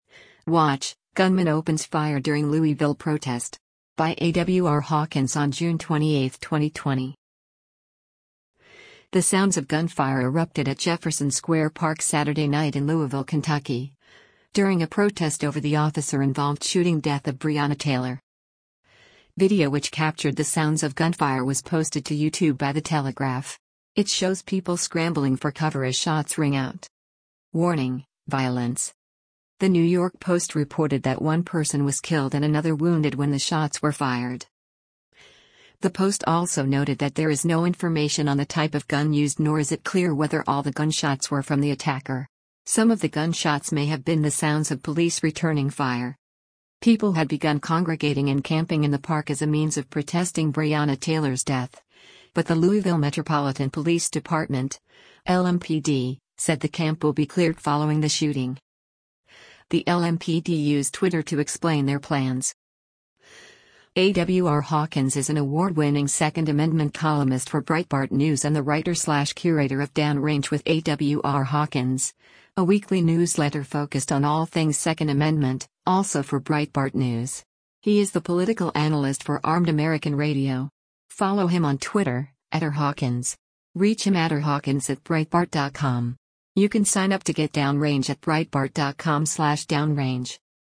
The sounds of gunfire erupted at Jefferson Square Park Saturday night in Louisville, Kentucky, during a protest over the officer-involved shooting death of Breonna Taylor.
It shows people scrambling for cover as shots ring out:
Some of the gun shots may have been the sounds of police returning fire.